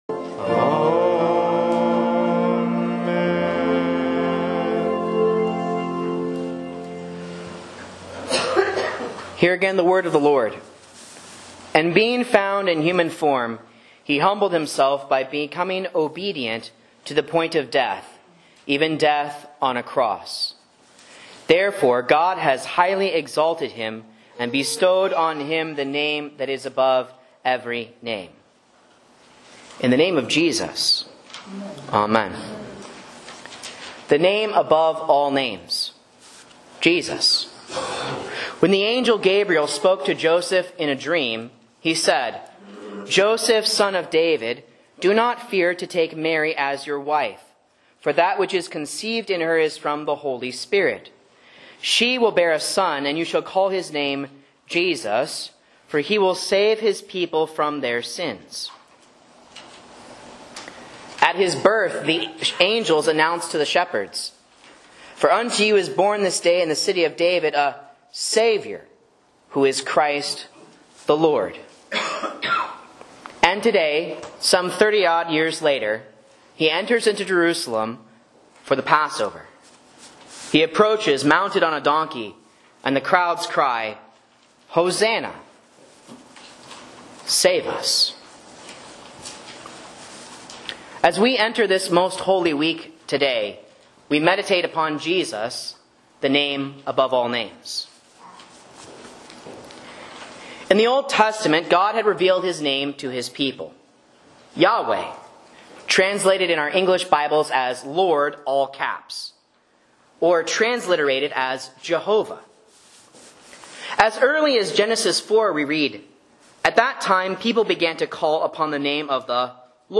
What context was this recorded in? Sermon and Bible Class Audio from Faith Lutheran Church, Rogue River, OR